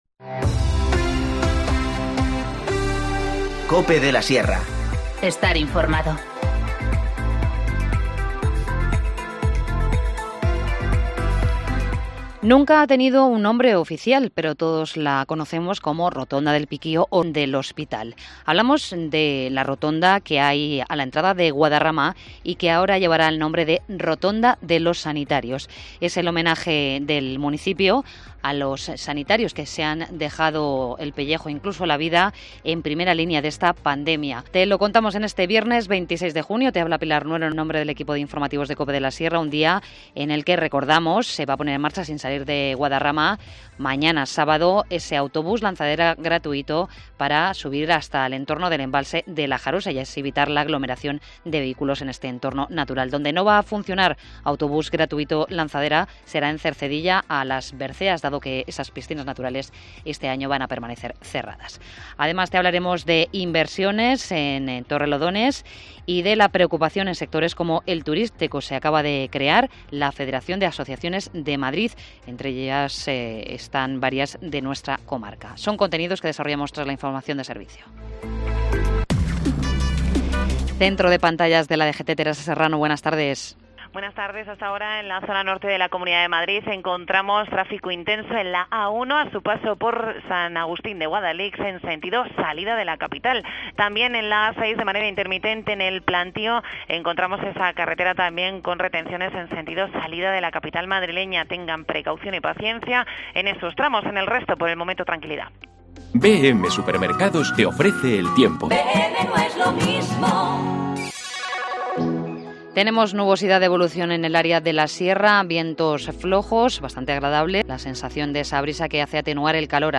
Informativo Mediodía 26 junio 14:20h